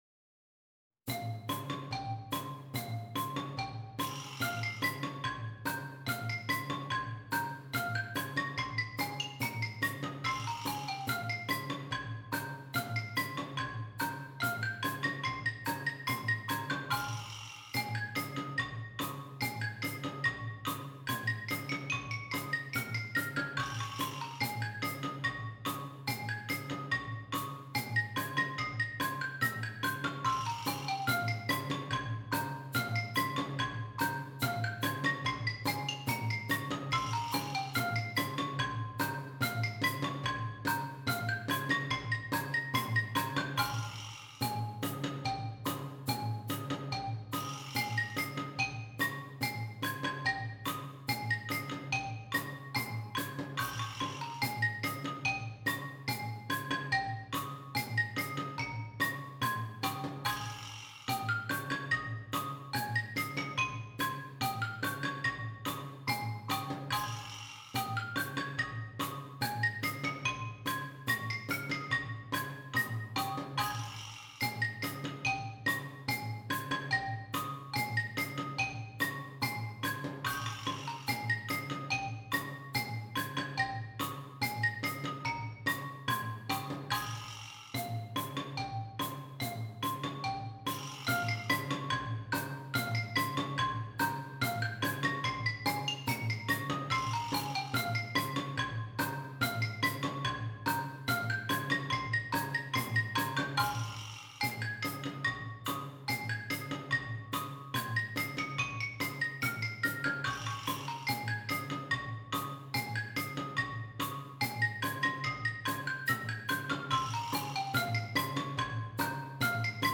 Percussion Ensemble